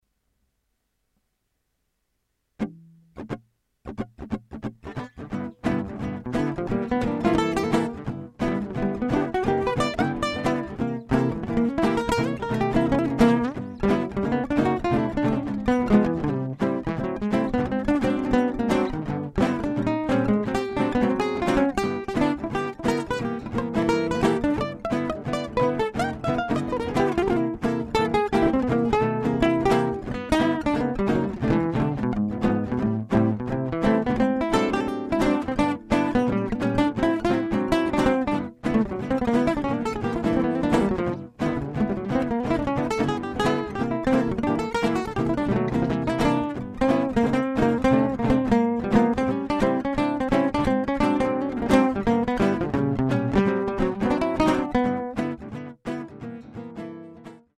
20:50:33 » ����������� ������������ �� ���� G7 �� ����� ��������� Gipsi-Bluse.mp3